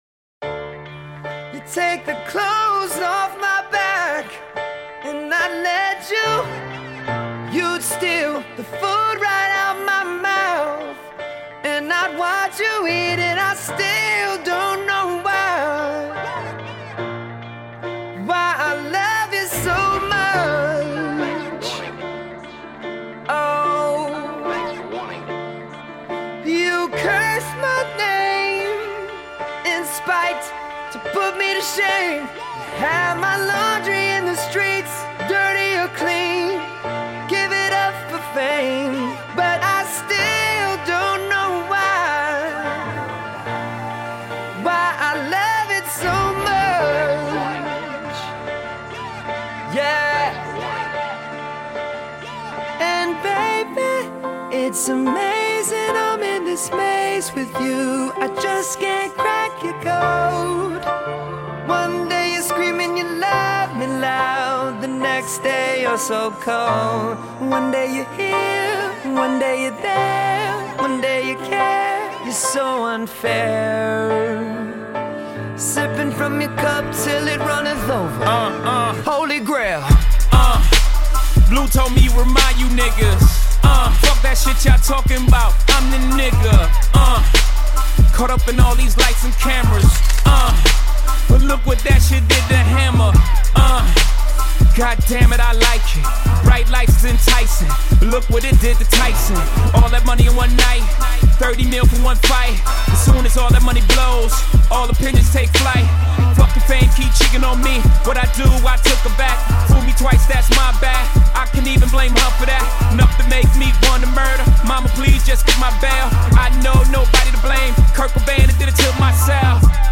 rap hip hop pop
آهنگ خوش ریتمیه